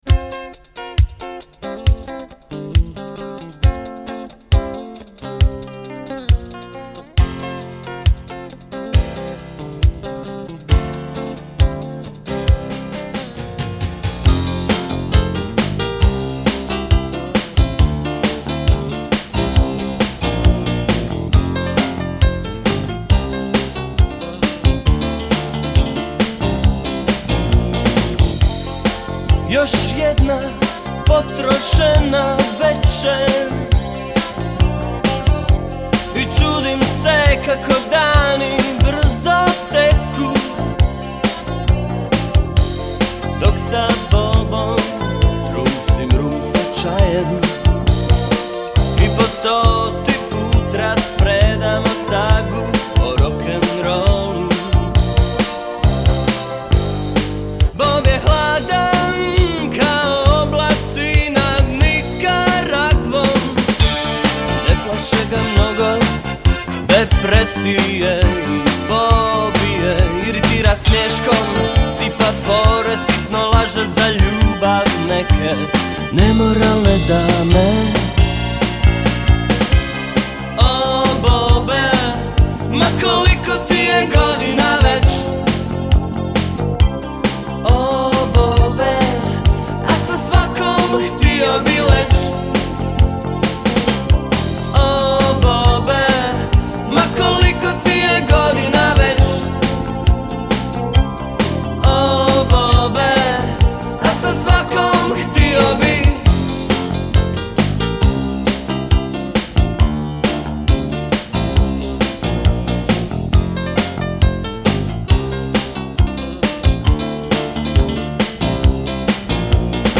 Rock - Pop